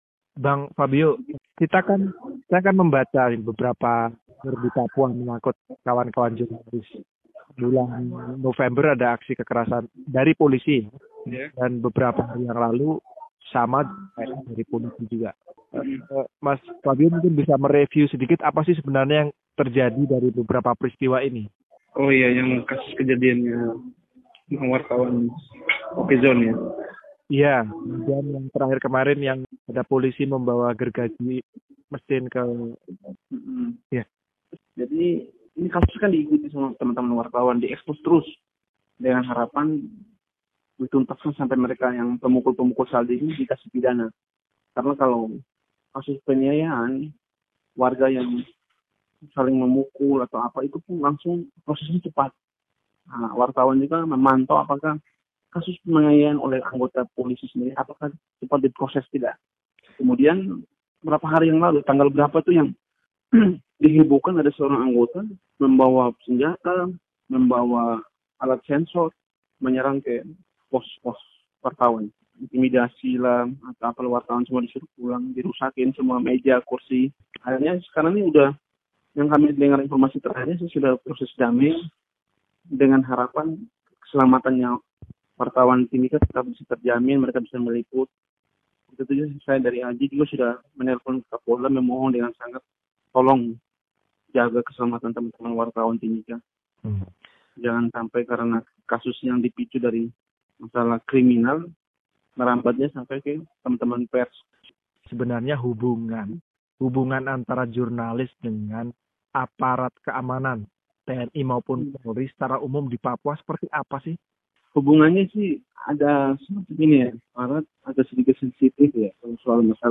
mewawancarai